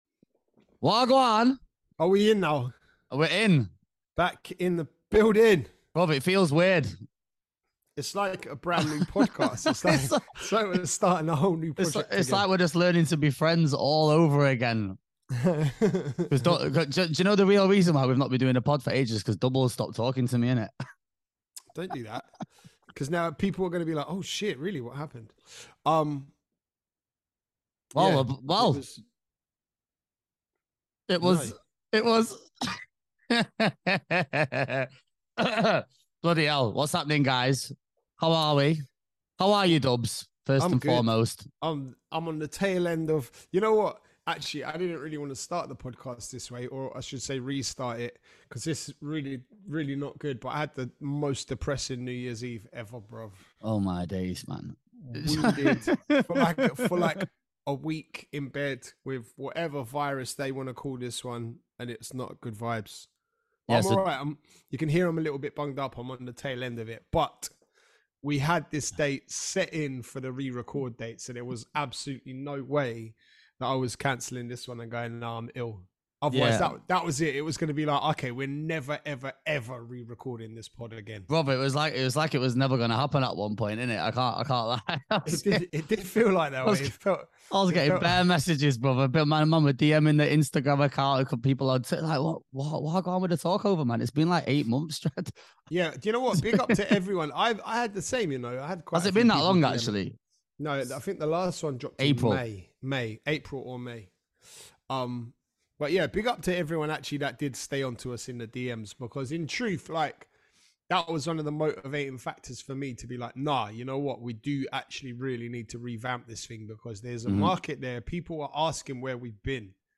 two DJs from the UK & we've decided that there needs to be an outlet for various topics relating to the DJ world and all things surrounding it…